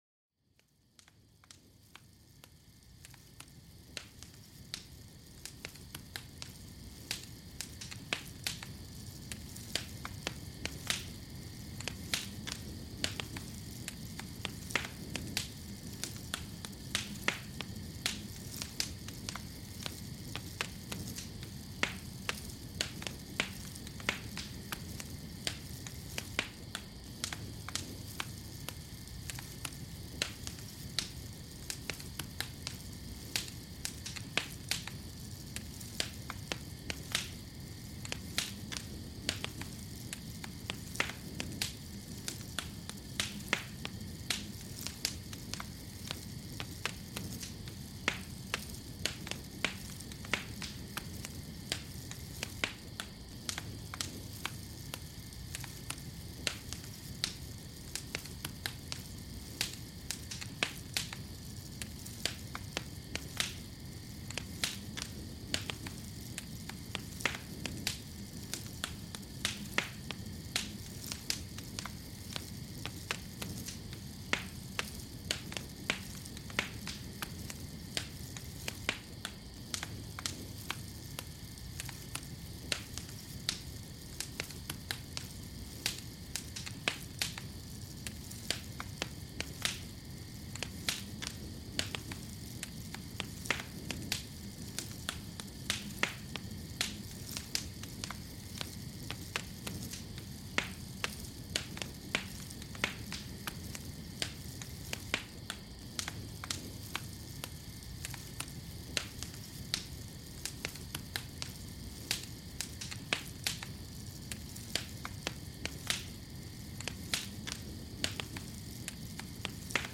Fuego de Campamento: Relajación y Calma Profunda
Escucha el tranquilizador crujido de un fuego de campamento bajo un cielo estrellado. Siéntete transportado al corazón de la naturaleza, rodeado de calidez y tranquilidad.